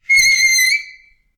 sounds_chalk_screech_03.ogg